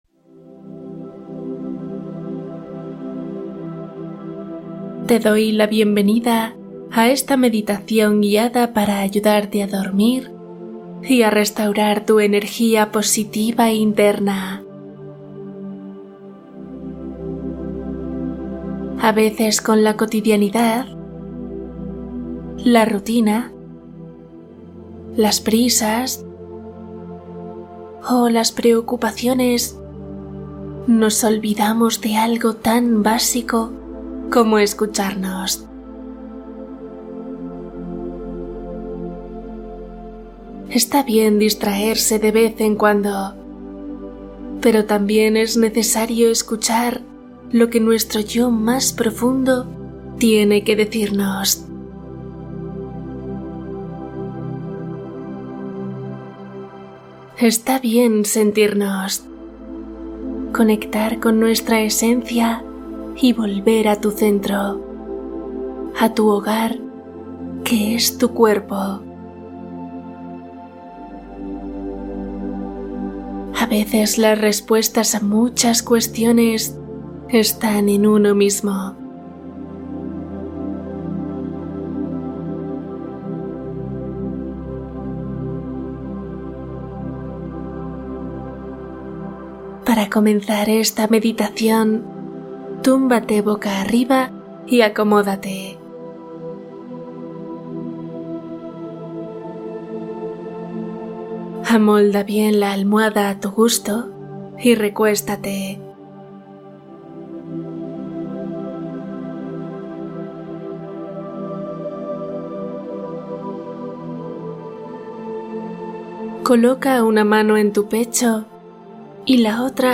Limpieza energética nocturna | Meditación guiada con chakras y afirmaciones